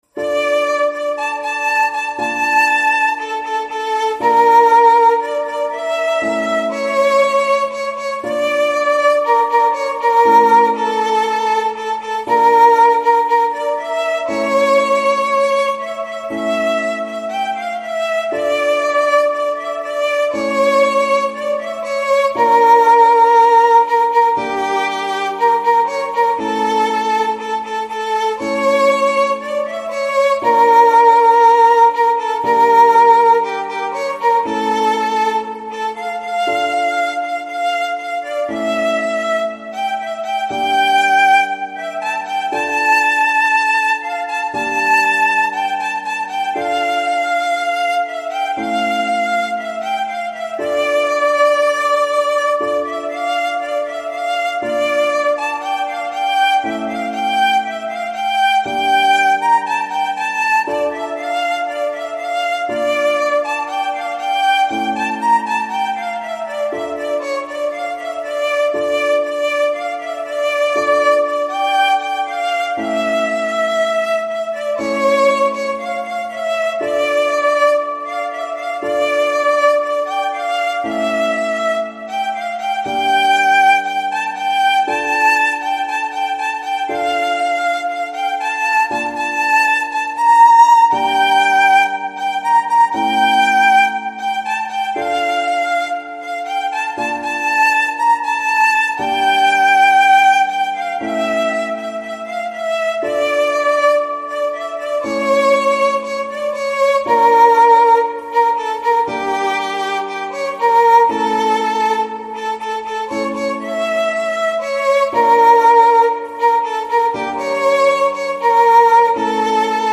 ساز : ویولون